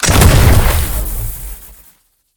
rifle2.ogg